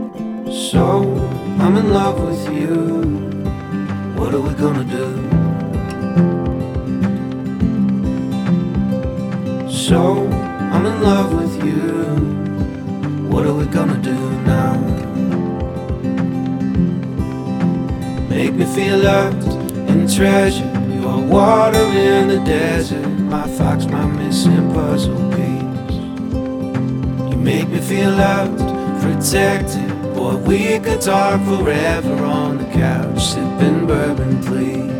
Жанр: Фолк-рок / Рок